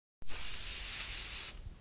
air freshener spray.ogg